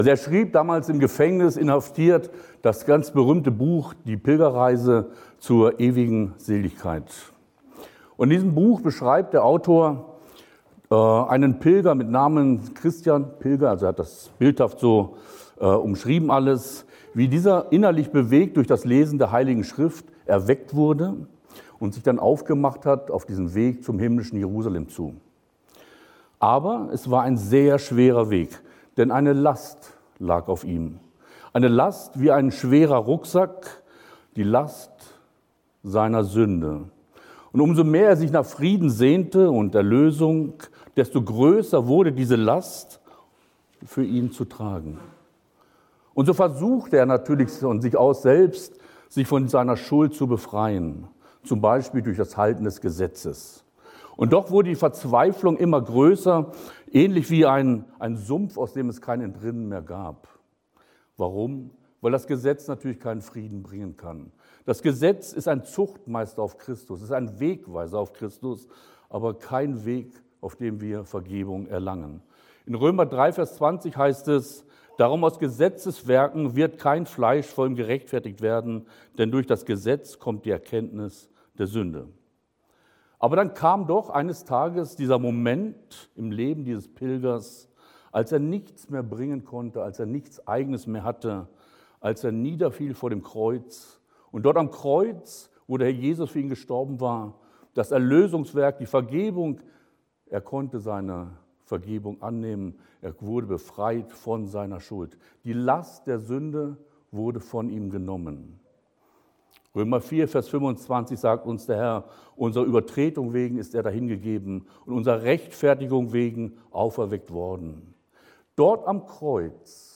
Heute predigte